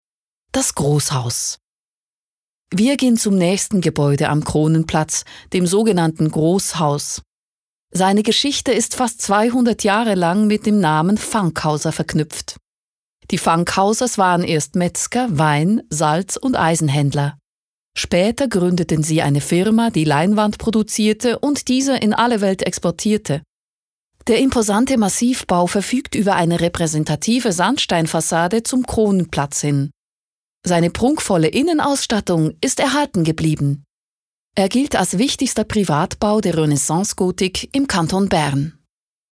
OFF-Kommentar Hochdeutsch (CH)
Sprecherin mit breitem Einsatzspektrum.